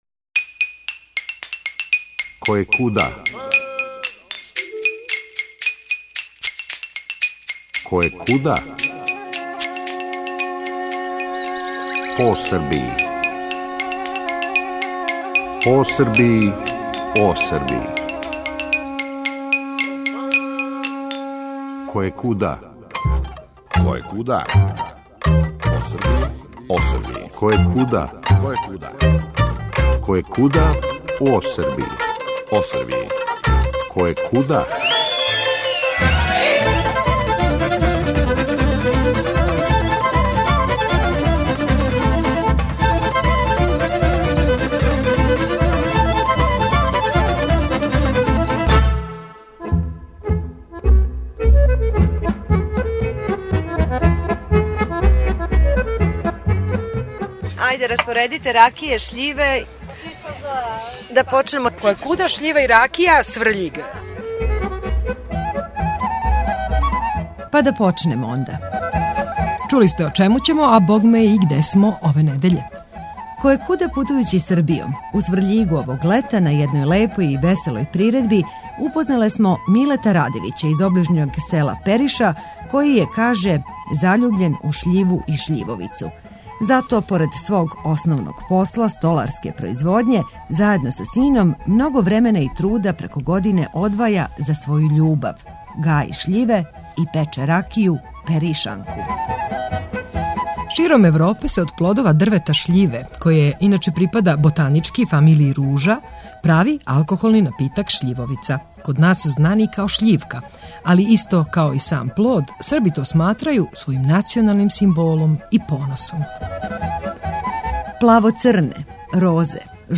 Репризирамо емисију, коју смо 2011. године забележили у Сврљигу, и у њој причу о шљиви и шљивовици!